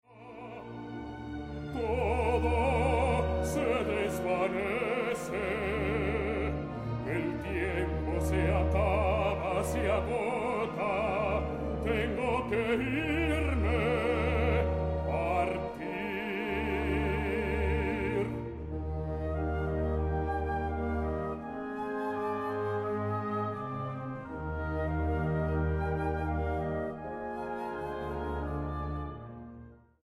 Grabado en: Teatro Aguascalientes, marzo, 2013.